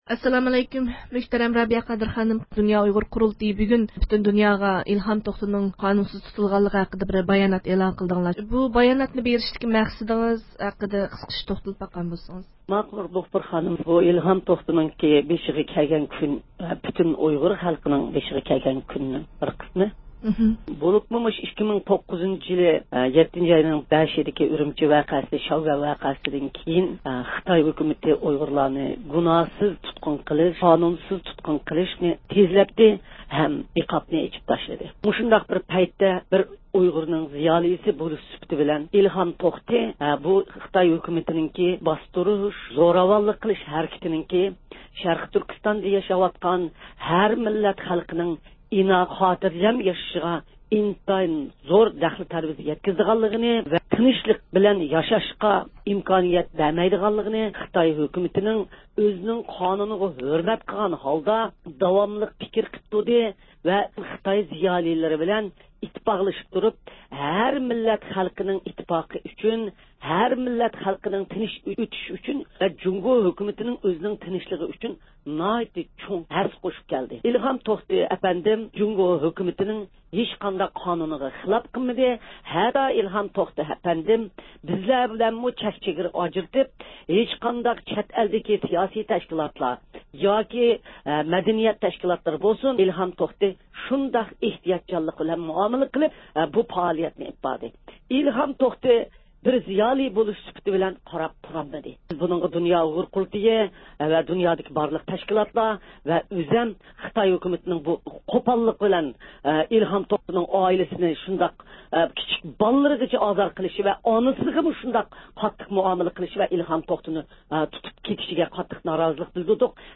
ئۇيغۇر مىللىي ھەرىكىتى رەھبىرى رابىيە قادىر خانىم 16-يانۋار بۇ ھەقتە ئىستانسىمىزدا رادىئو باياناتى بېرىپ، خىتاينى «ئۇيغۇرلارنىڭ ئىلھام توختىغا ئوخشاش زىيالىيلىرىنى قالايمىقان تۇتقۇن قىلىشنىڭ ئېلىپ كېلىدىغان ئاقىۋىتى ئېغىر بولىدۇ» دەپ ئاگاھلاندۇردى.